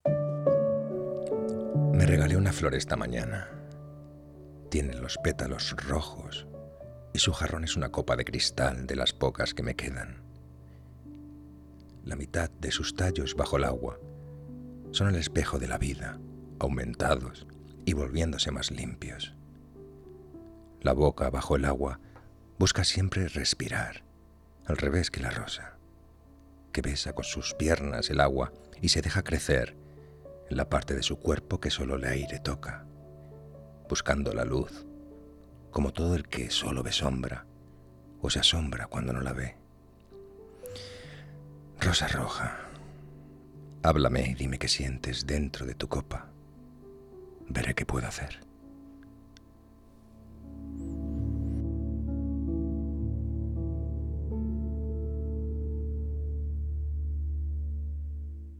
ME-REGALE-UNA-FLOR-ESTA-MANANA.-POEMA-10-DE-ENERO-.mp3